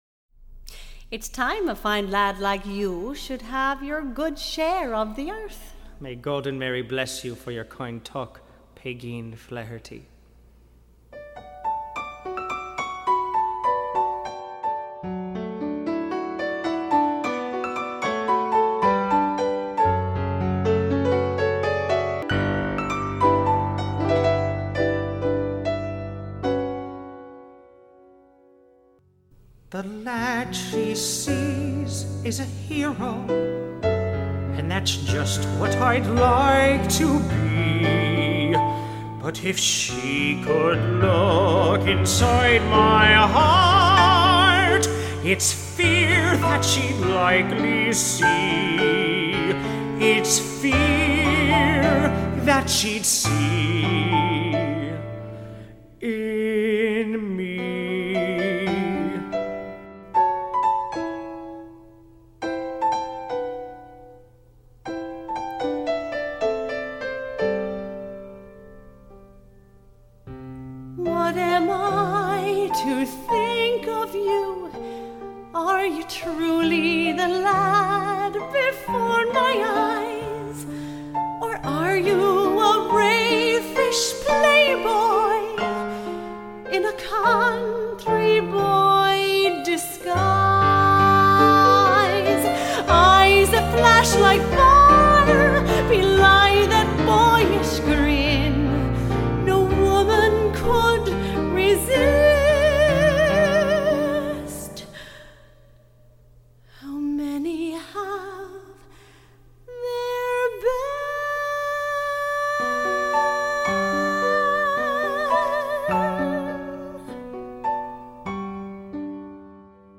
- An Irish Musical Comedy
(Christy, Pegeen)